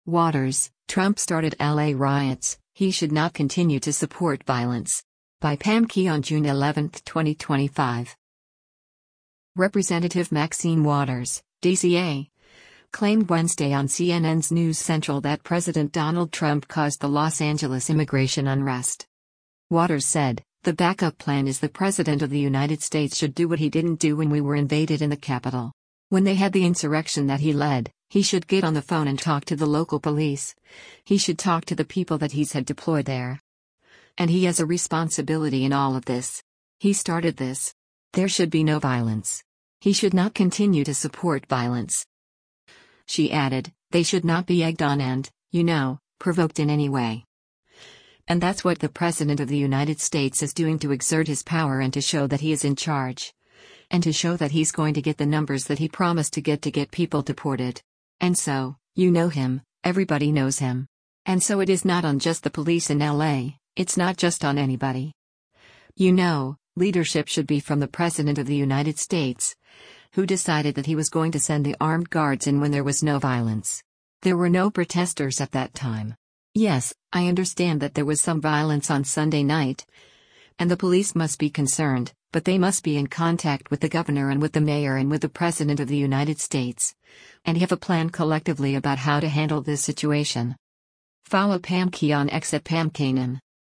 Representative Maxine Waters (D-CA) claimed Wednesday on CNN’s “News Central” that President Donald Trump caused the Los Angeles immigration unrest.